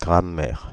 • ÄäntäminenFrance (Paris):
• IPA: [la ɡʁa.mɛʁ]